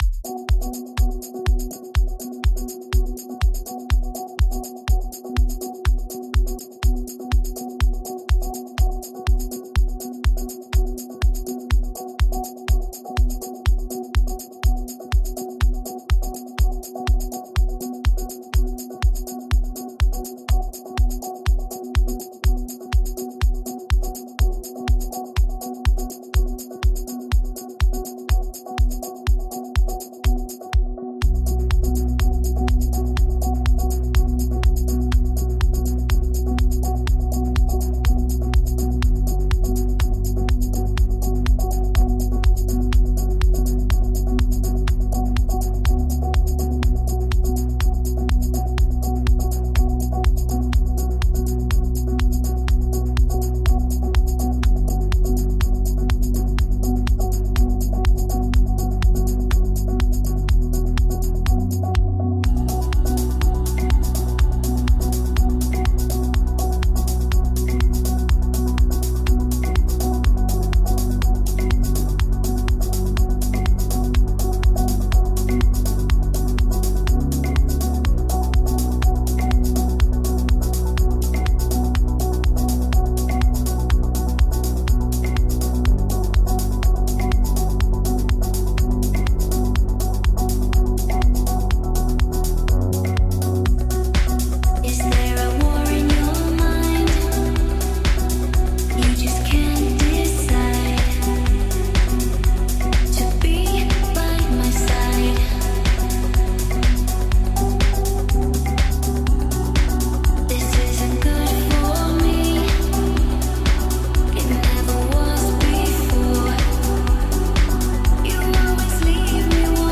Melodic House/Breaks music
Progressive House
Deep House